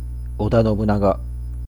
Oda Nobunaga (織田 信長, [oda nobɯ(ꜜ)naɡa]
Ja-oda_nobunaga.ogg.mp3